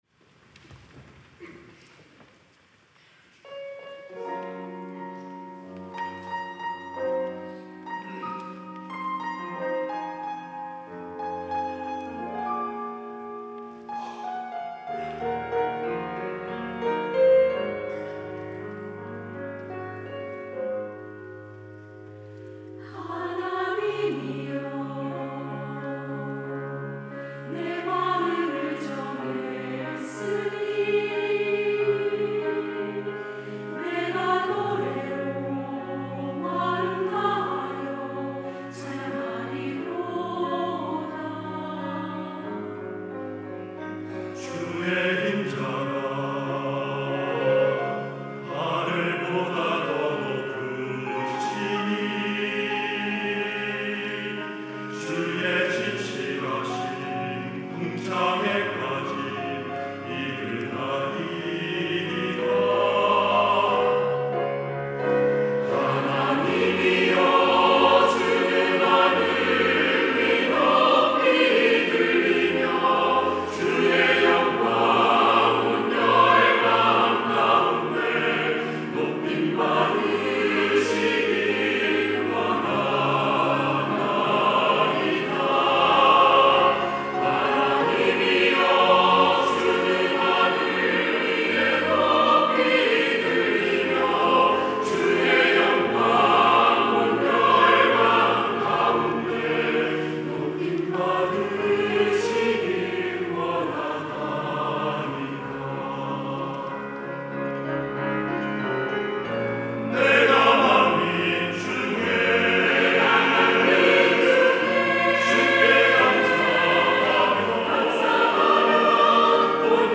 할렐루야(주일2부) - 시편 108편
찬양대